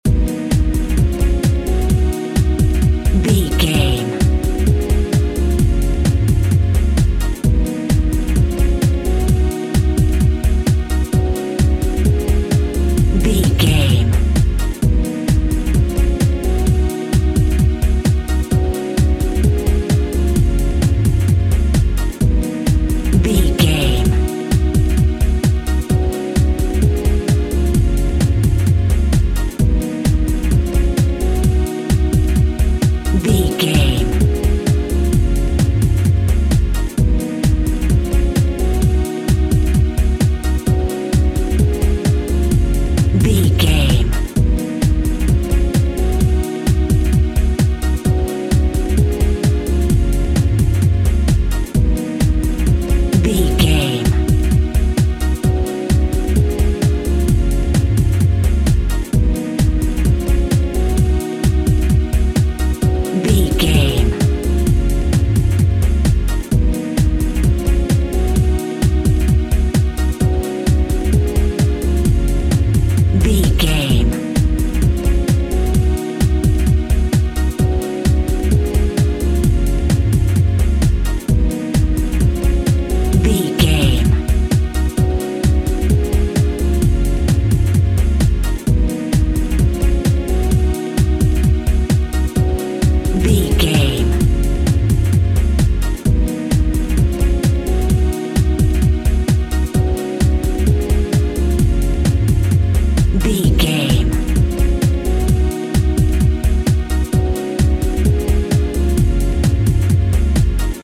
Ionian/Major
G♭
Fast
groovy
futuristic
funky
energetic
synthesiser
strings
bass guitar
electric guitar
electric piano
drum machine
ambient
electronic